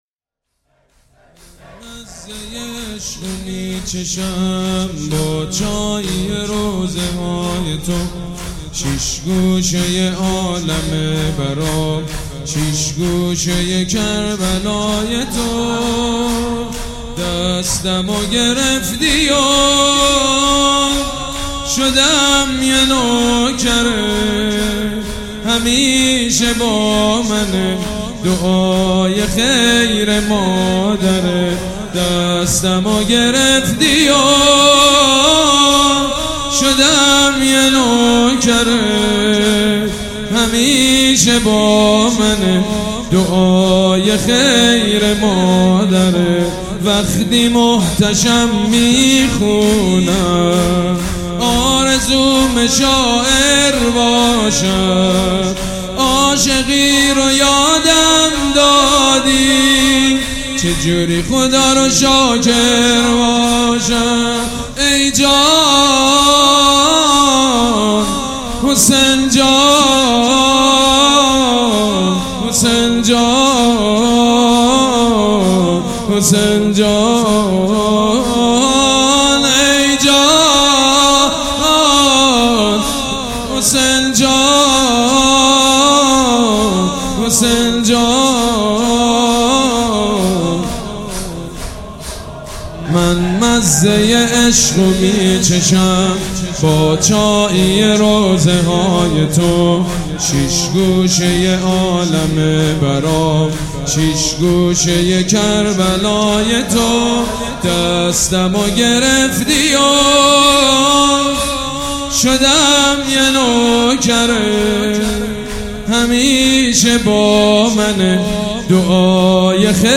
به مناسبت ایام ماه صفر ،گلچینی از مداحی های حاج سید مجید بنی فاطمه که در حسینیه ی ریحانه الحسین (س) اجرا شده است تقدیم شما همراهان همیشگی مداحی آنلاین می گردد.